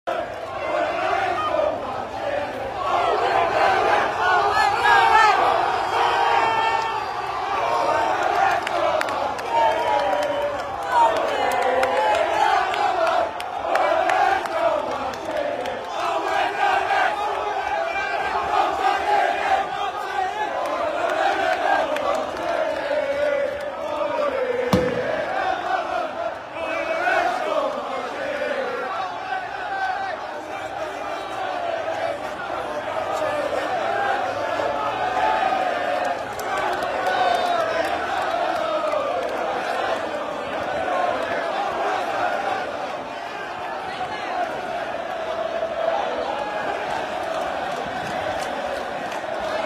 Liverpool FC fans welcome the team bus ahead of today’s Premier League match against Tottenham Hotspur at Anfield